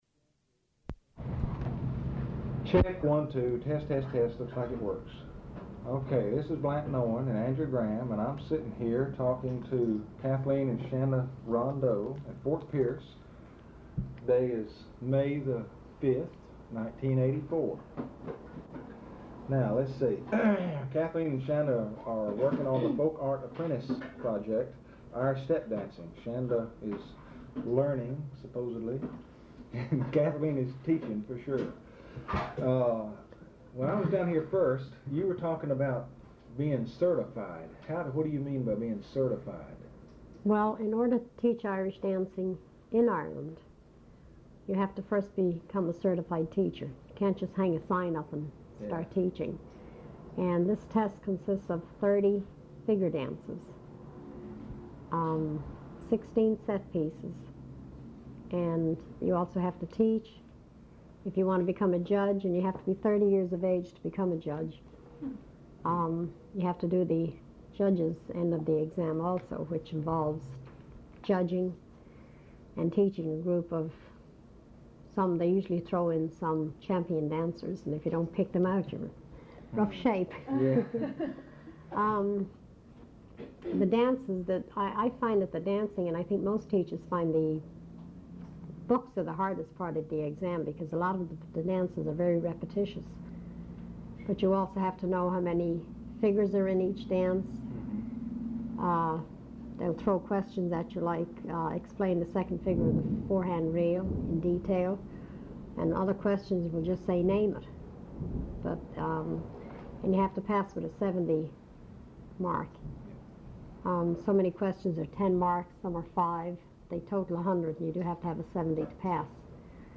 One audio cassette.